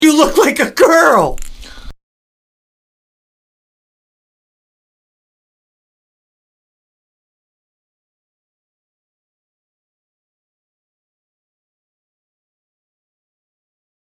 Category: Comedians   Right: Personal
Tags: Pete Correale Sebastian Maniscalco Comedy Podcast